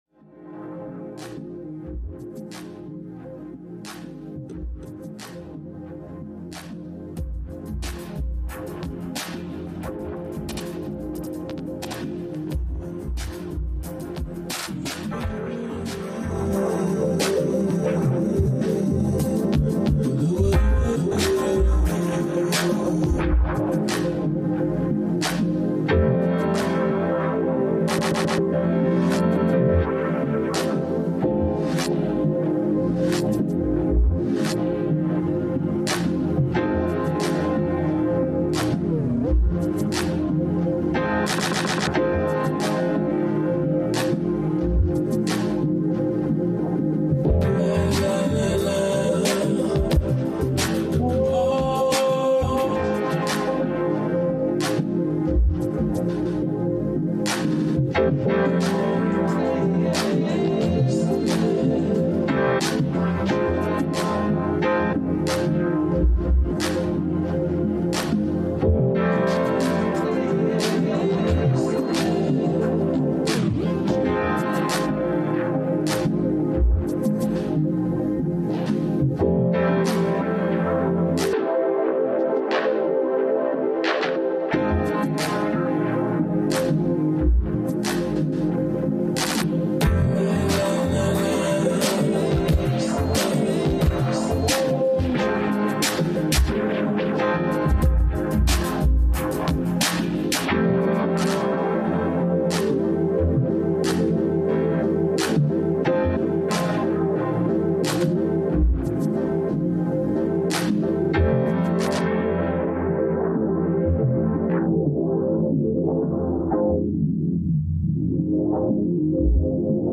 Beats Electronic Hip Hop